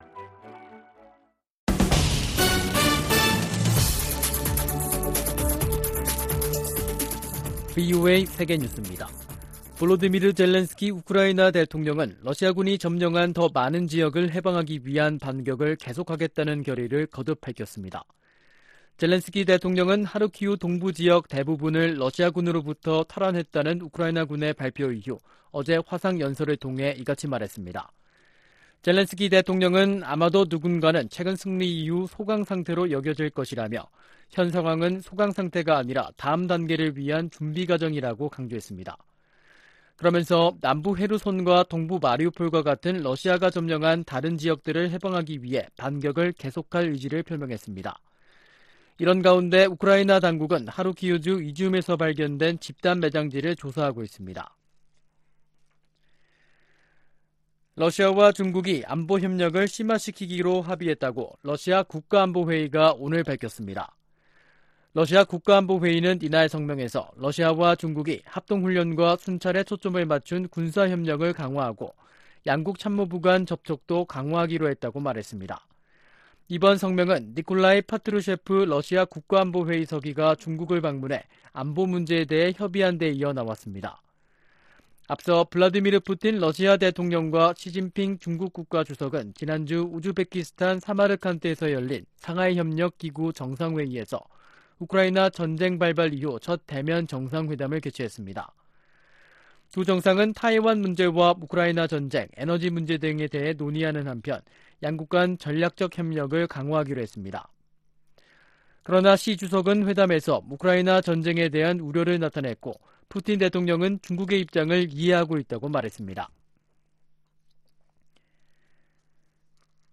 VOA 한국어 간판 뉴스 프로그램 '뉴스 투데이', 2022년 9월 19일 3부 방송입니다. 미국은 대북 억제를 위해 전략자산의 효과적인 역내 전개와 운용이 지속되도록 한국과의 공조 강화를 약속했습니다. 미 해군은 로널드 레이건 항공모함이 부산에 입항해 한국군과 연합훈련할 계획이라고 밝혔습니다. 제77차 유엔총회에서 미국은 식량 안보와 보건 협력, 안보리 개혁 문제를 주요 우선순위로 다룹니다.